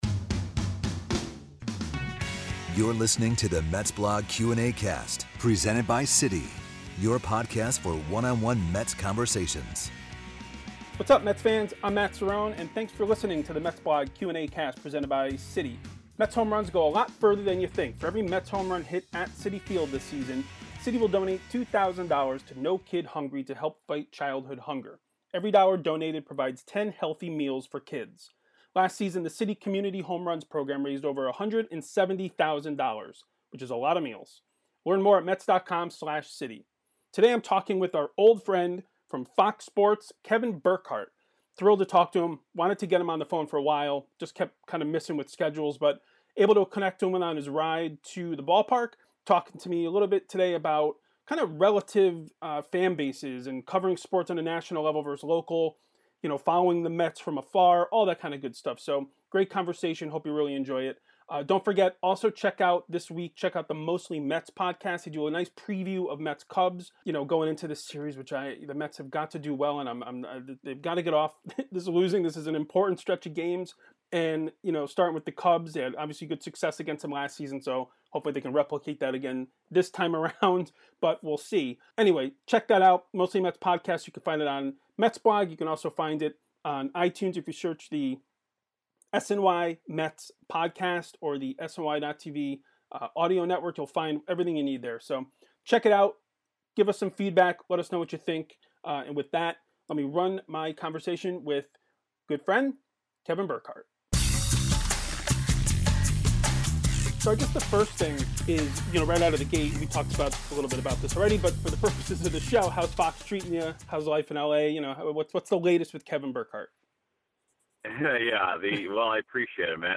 MetsBlog Q&Acast: Kevin Burkhardt interview